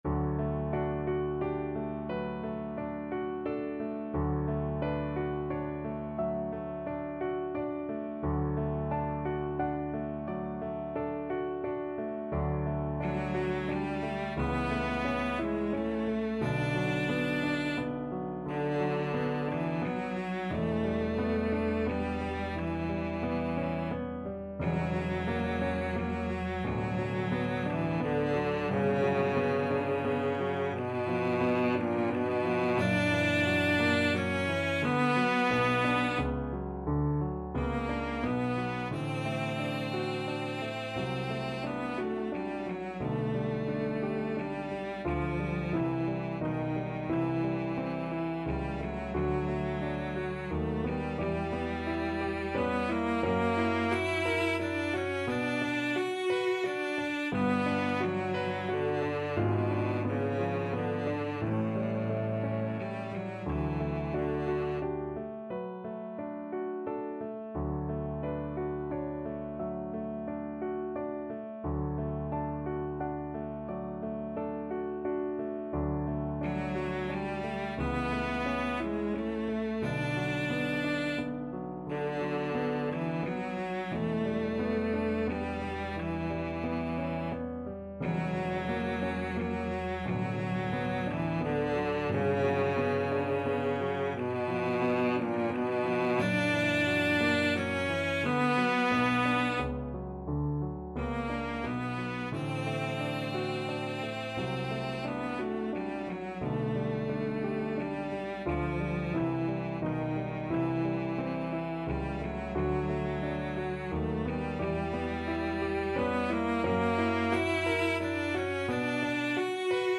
Cello version
6/4 (View more 6/4 Music)
~ = 88 Andante
Classical (View more Classical Cello Music)